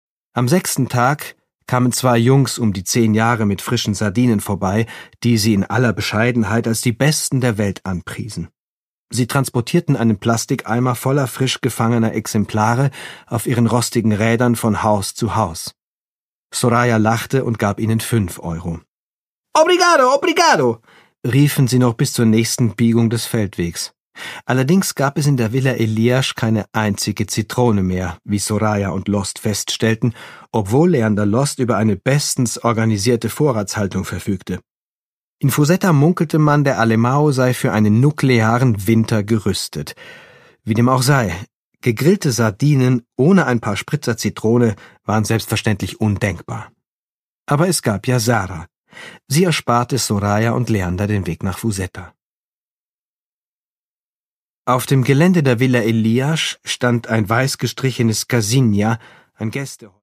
Produkttyp: Hörbuch-Download
Gelesen von: Andreas Pietschmann